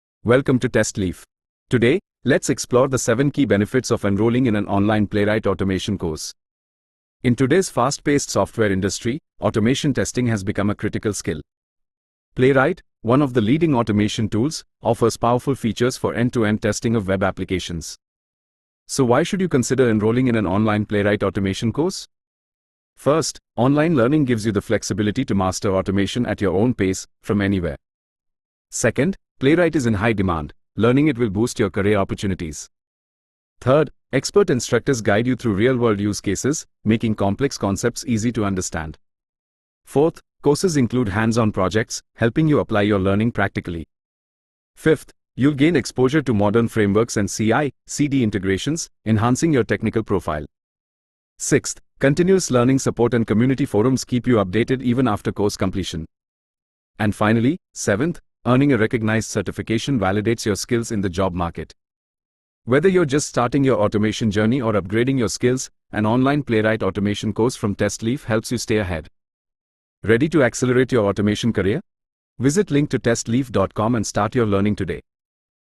AudioTTS-Simple-Text-to-Speech-Downloader.ogg